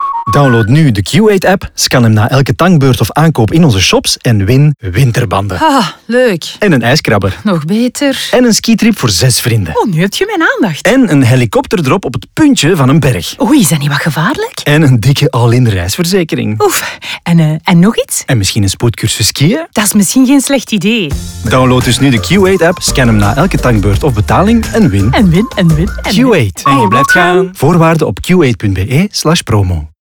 Om de toekomstige winnaar te laten weten dat de wedstrijd loopt, kiest VML voor radiospots & ads op social & POS-communicatie.